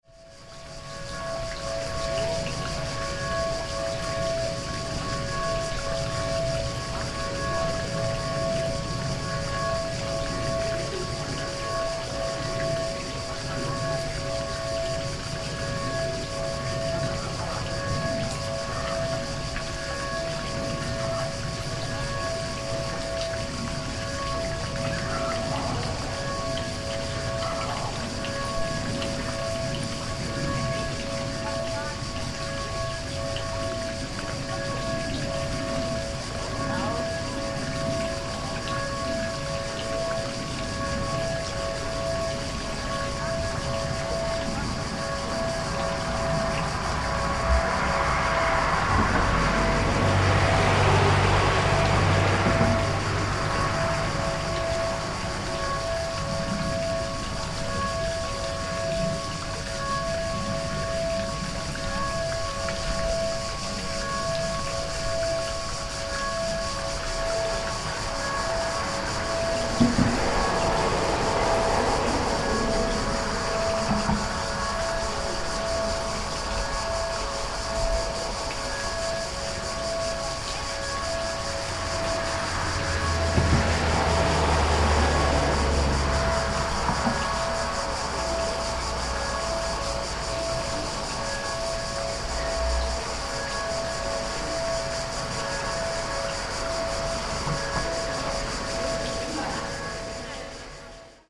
Bells at Villa Barbarigo
Villa Barbarigo (also known as Villa Barbarigo Pizzoni Ardemani from its various proprietors) is a 17th-century rural villa in northern Italy, with historic gardens, fountains and beautiful grounds. Here we're standing at the old entrance gates to the villa, through which people would have arrived somewhat grandly by boat. Today's soundscape is the distant bells from nearby Valsanzibio drifting across the air, underpinned by the chirping of cicadas, but in 2020 the sound is punctuated by passing cars, instead of boats.